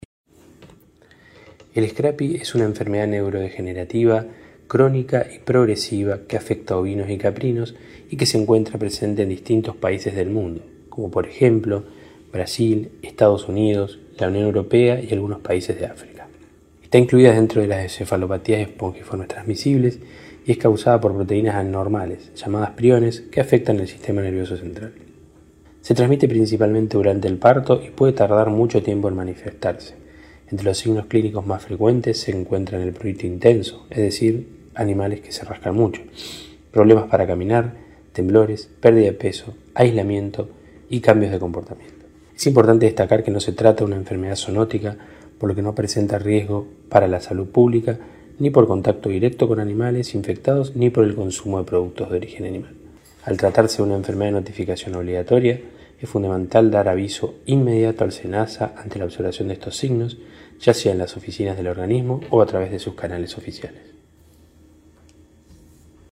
En declaraciones distribuidas a la prensa, funcionario nacional describió la enfermedad e hizo referencia a la detección de casos y a las medidas adoptadas por SENASA para su prevención y control.